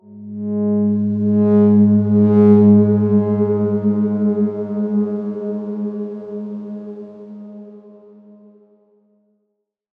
X_Darkswarm-G#2-mf.wav